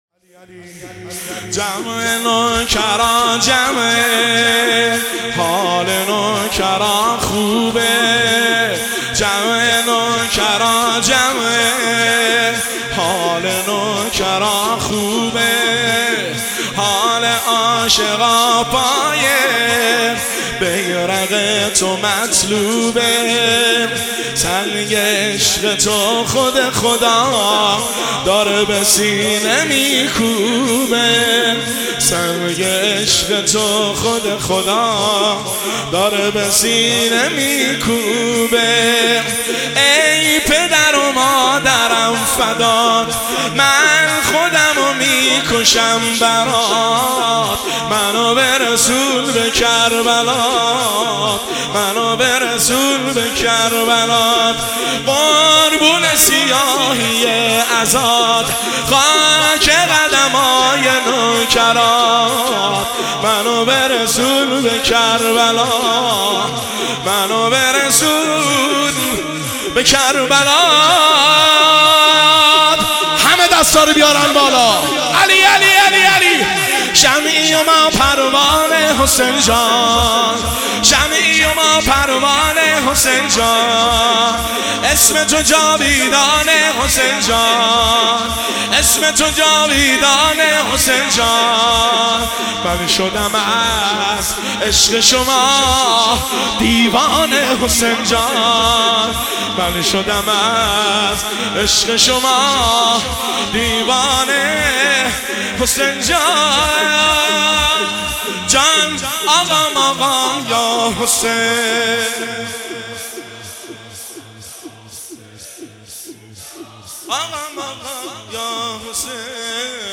شور شب سوم محرم الحرام 1404